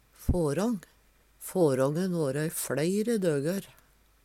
ng - lyden er ikkje rein ng lyd, ly til lydfil